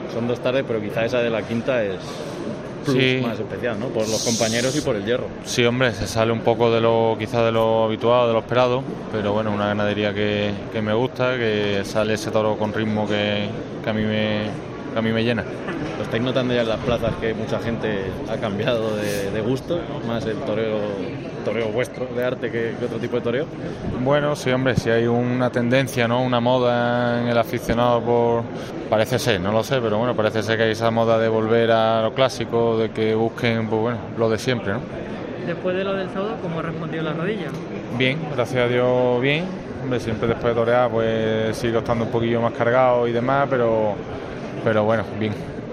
El Albero de COPE habló con los principales protagonistas que acudieron a la gala de presentación de la Feria de San Isidro 2022